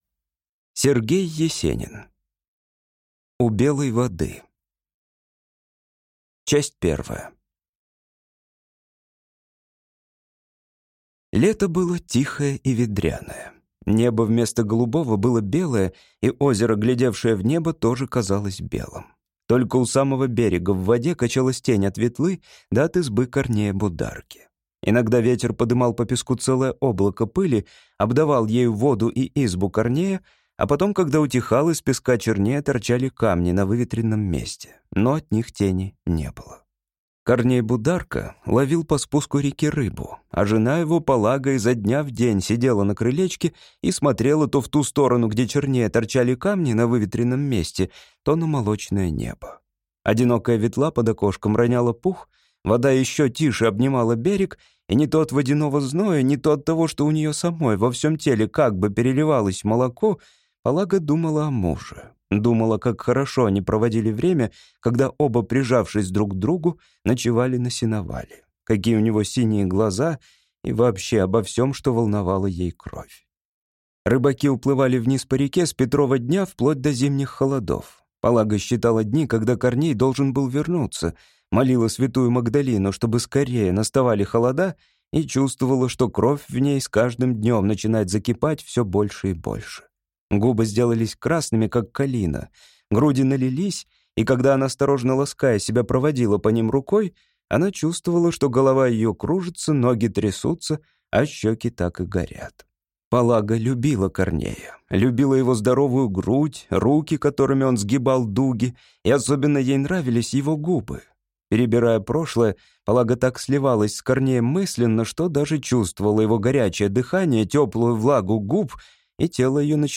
На данной странице вы можете слушать онлайн бесплатно и скачать аудиокнигу "У Белой воды" писателя Сергей Есенин.